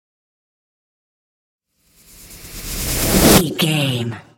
Trailer raiser
Sound Effects
Fast paced
In-crescendo
Atonal
bouncy
bright
driving
futuristic
intense
tension
dramatic
riser